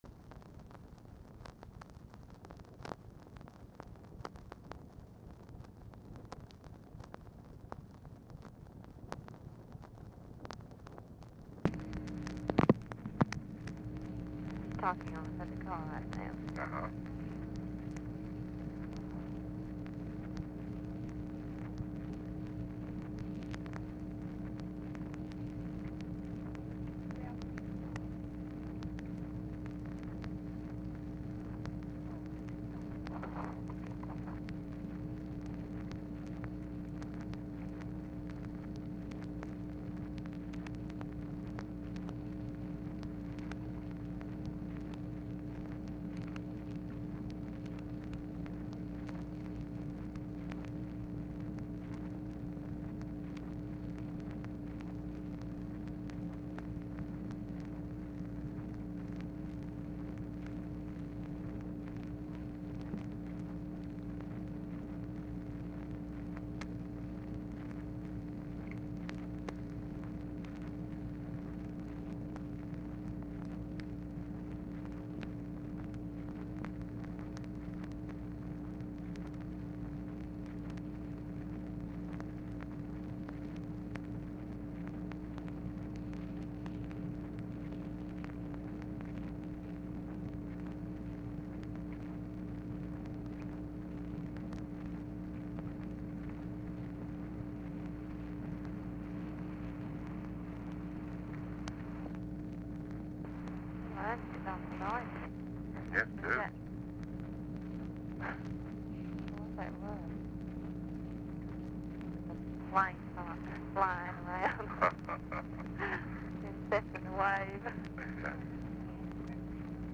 FARMER ON HOLD 3:20, DISCUSSES NOISES ON TELEPHONE LINE WITH TELEPHONE OPERATOR WHILE ON HOLD; OFFICE SECRETARY APOLOGIZES FOR WAIT
Format Dictation belt
Location Of Speaker 1 LBJ Ranch, near Stonewall, Texas
Specific Item Type Telephone conversation